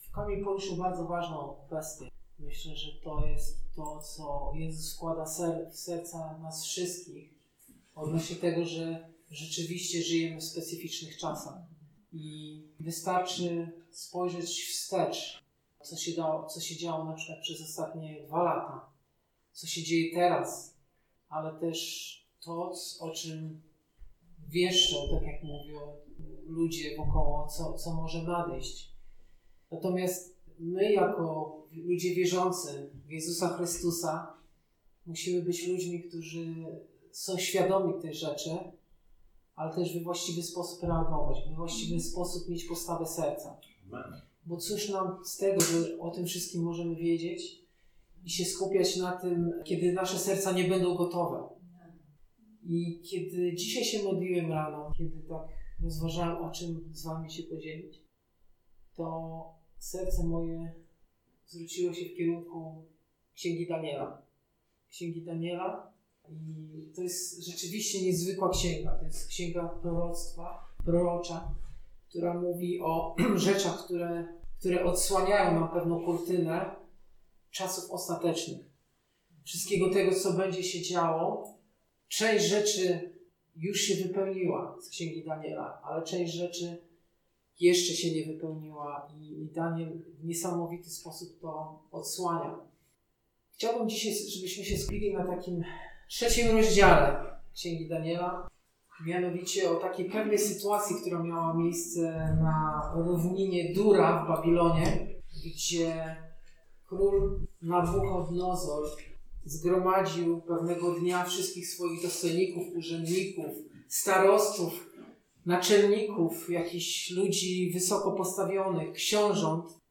Posłuchaj kazań wygłoszonych w Zborze Słowo Życia w Olsztynie.